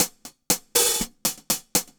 Index of /musicradar/ultimate-hihat-samples/120bpm
UHH_AcoustiHatB_120-01.wav